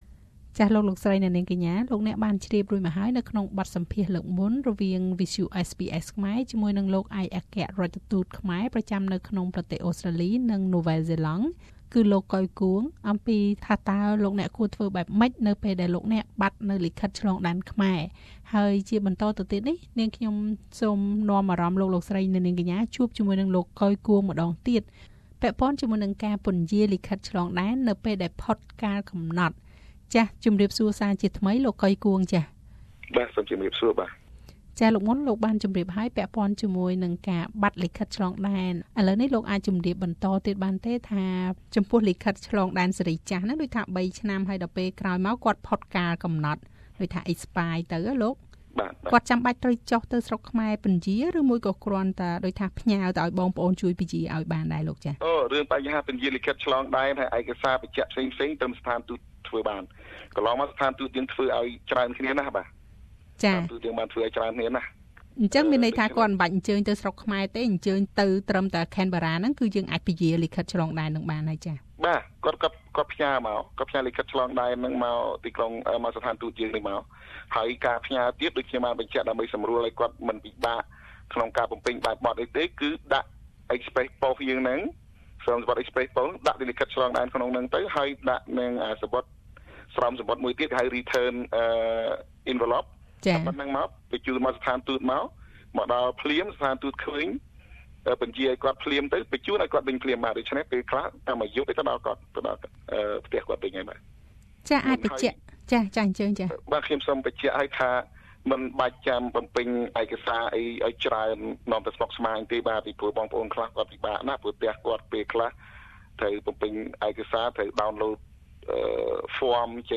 លោក កុយ គួង ឯកអគ្គរាជទូតខ្មែរប្រចាំនៅប្រទេសអូស្រ្តាលី និងណូវ៉ែលហ្សេឡង់បញ្ជាក់ប្រាប់SBSខ្មែរថា ការពន្យារពេល លិខិតឆ្លងដែនខ្មែរដែលផុតកាលកំណត់ធ្វើឡើងដោយឥតគិតថ្លៃ និងមិនចាំបាច់បំពេញឯកសារស្មុគស្មាញអ្វីឡើយ។សូមស្តាប់នូវបទសម្ភាសន៍ពិស្តារ។
Koy Kuong, Cambodian Ambassador to Australia and New Zealand Source: Facebook